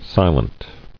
[si·lent]